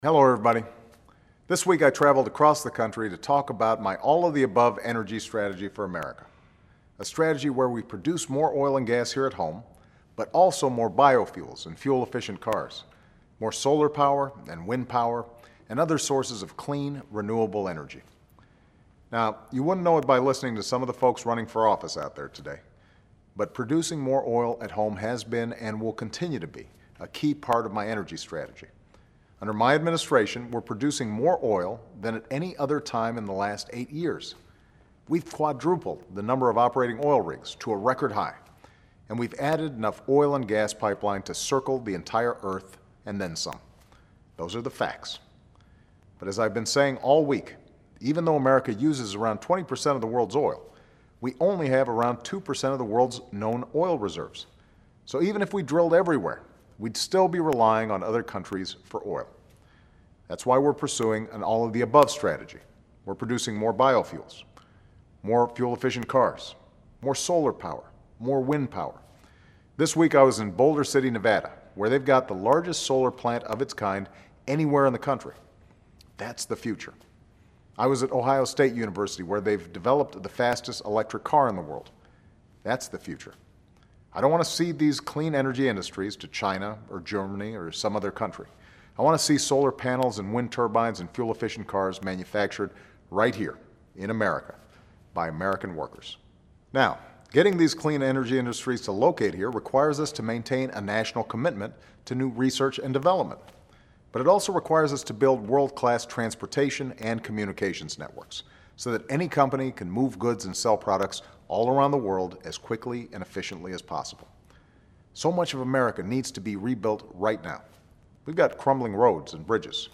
演讲听力材料03.25
Remarks of President Barack Obama